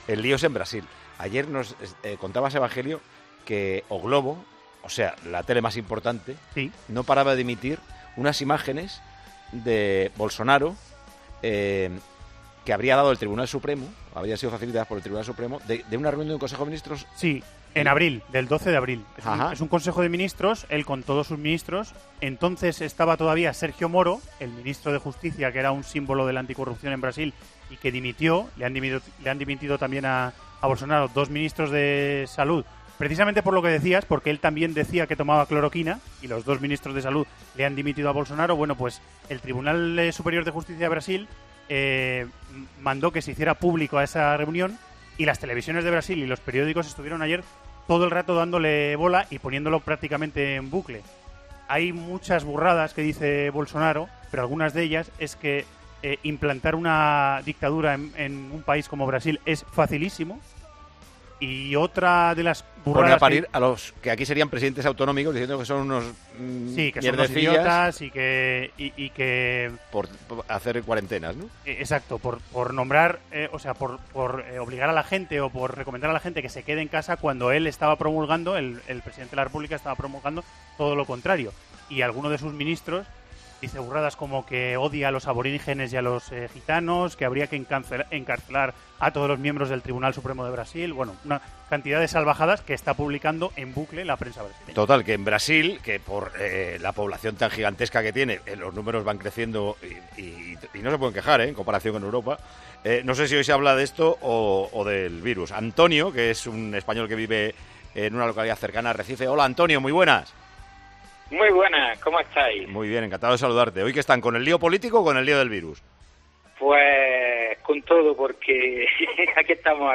un español que reside en Brasil
Con Paco González, Manolo Lama y Juanma Castaño